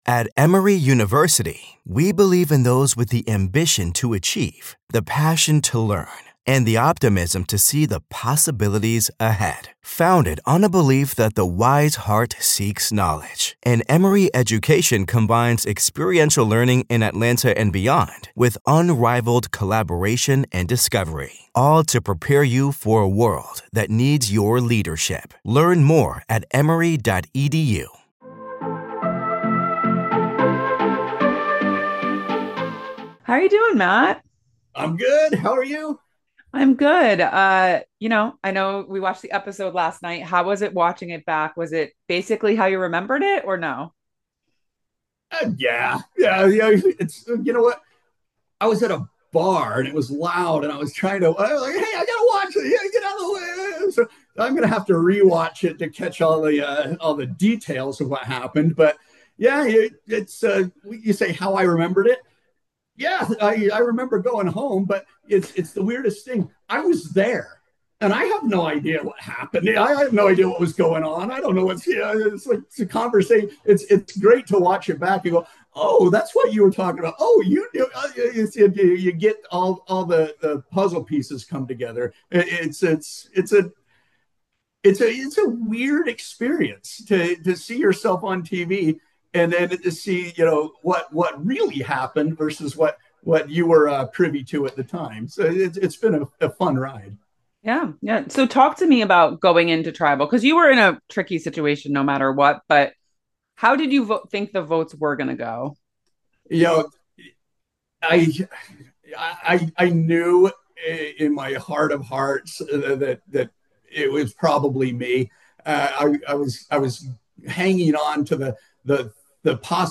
Survivor 49 Exit Interview: Fifth Player Voted Out Speaks!